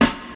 Puffy Snare Sample D# Key 26.wav
Royality free snare drum tuned to the D# note. Loudest frequency: 1628Hz
puffy-snare-sample-d-sharp-key-26-0EP.mp3